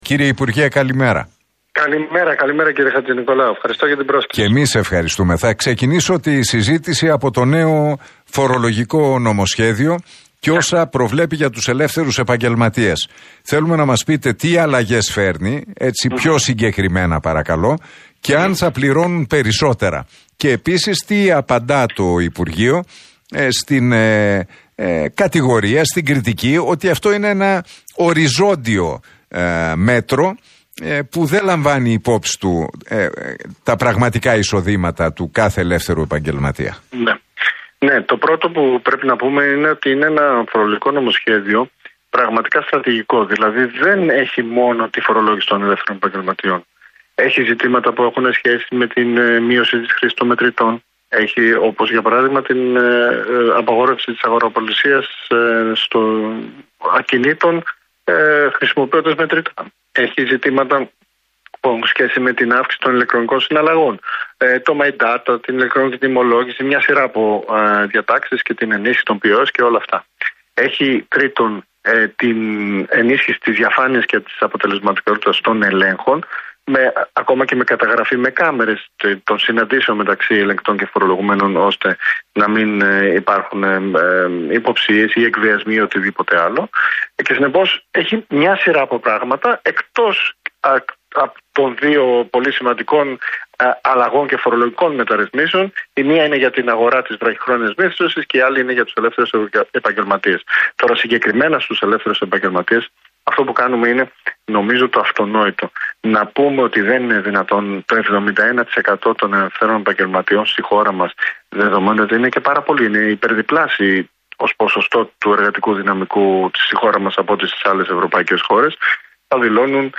Για το φορολογικό νομοσχέδιο και τις αλλαγές που φέρνει για τους ελεύθερους επαγγελματίες μίλησε ο υφυπουργός Οικονομικών, Χάρης Θεοχάρης στον Realfm 97,8 και τον Νίκο Χατζηνικολάου.